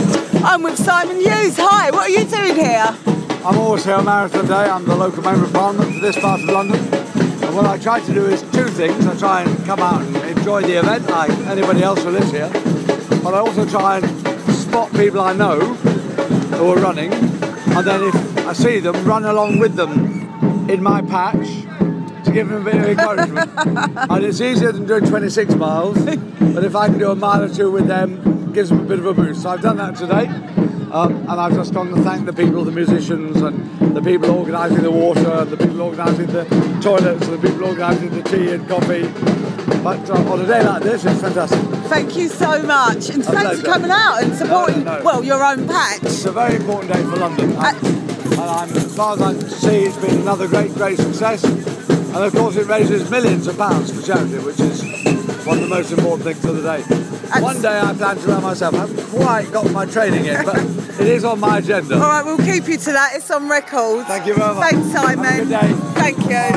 Simon Huges MP for Bermondsey says he'll run next year, he thanks everyone who came out to help today.